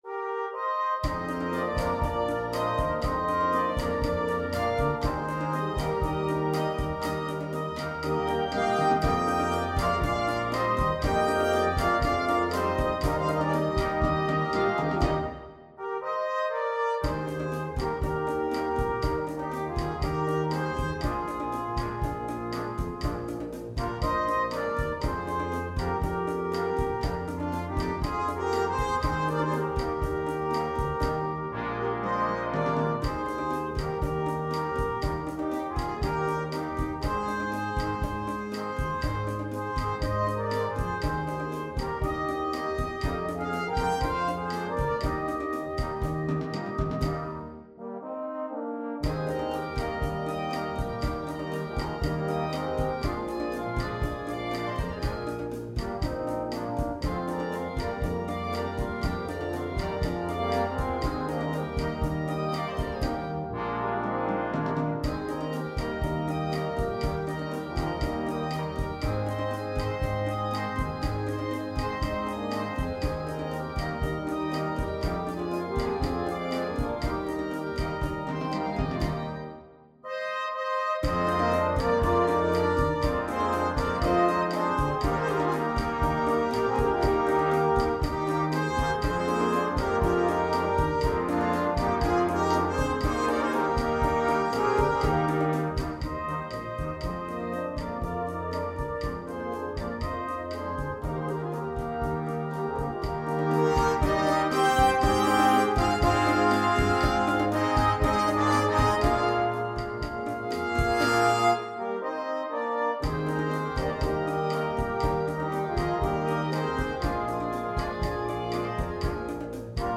sans instrument solo
Musique légère
Rumba / Rock